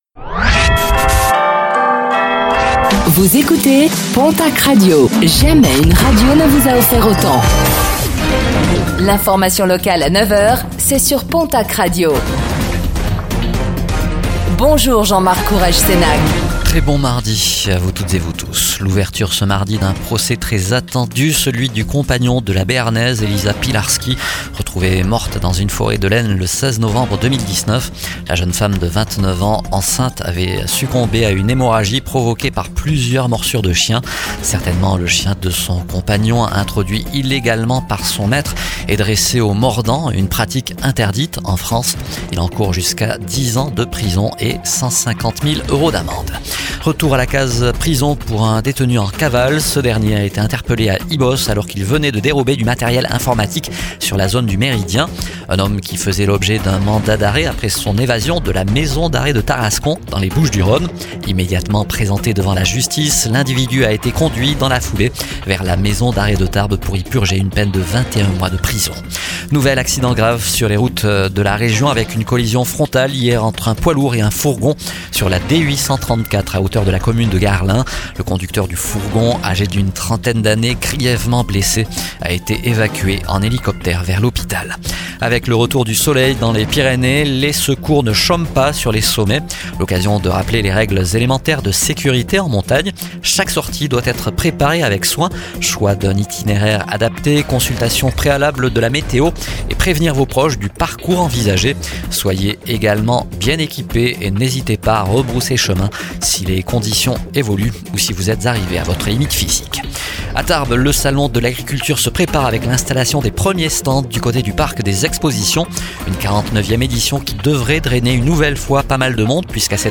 Infos | Mardi 03 mars 2026